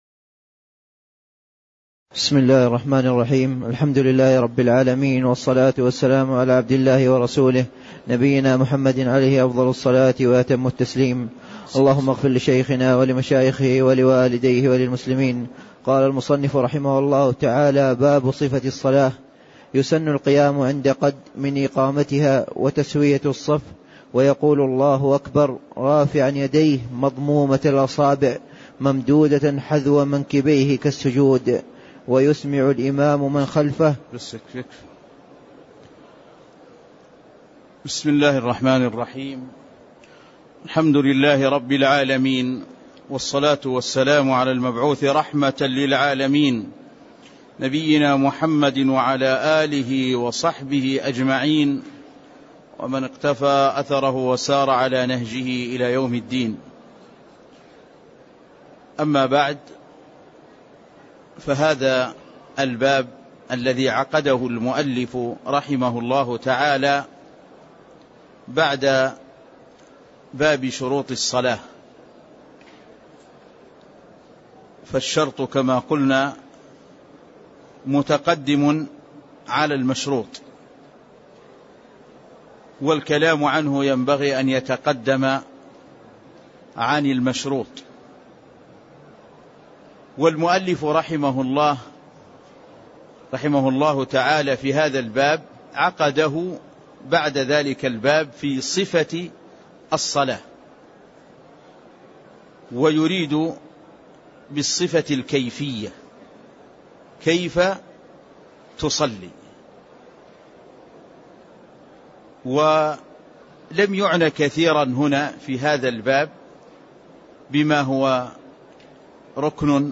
تاريخ النشر ٢٦ رجب ١٤٣٥ هـ المكان: المسجد النبوي الشيخ